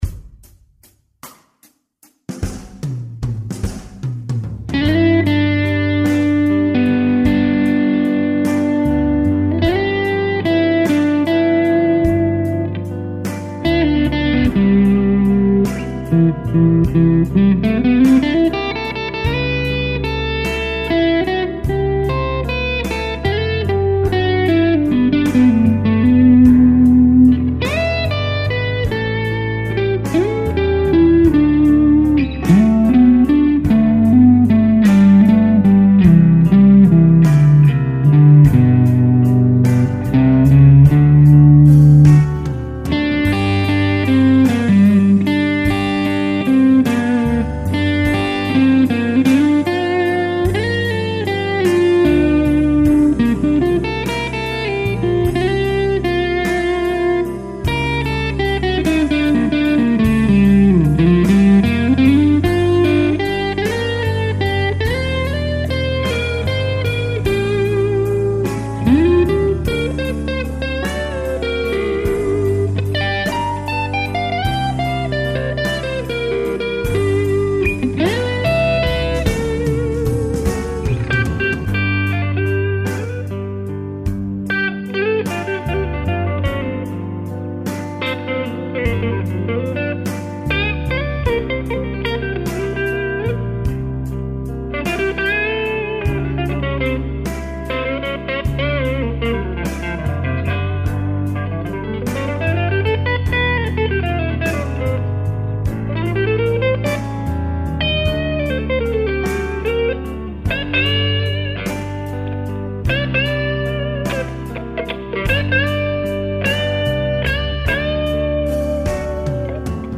Slow Blues Jam
Jamble_Jam_Slow_Blues.mp3